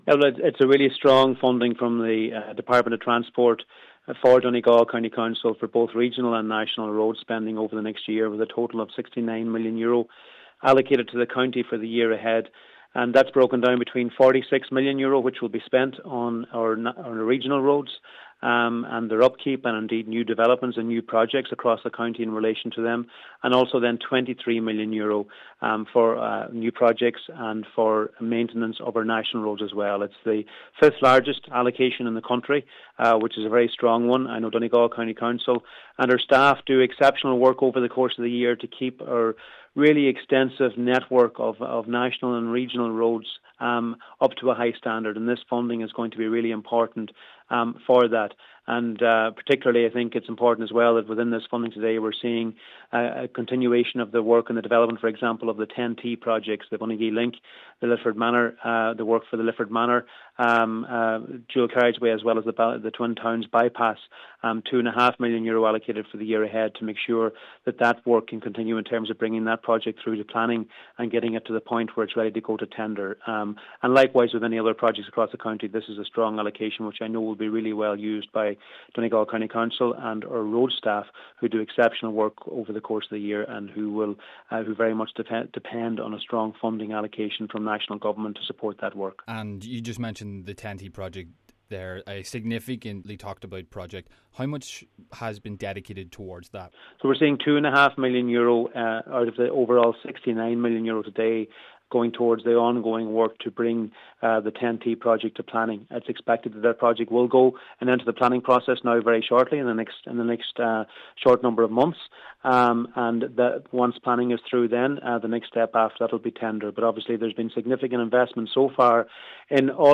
Junior Minister, Charlie McConalogue has called today’s announcement important for the county: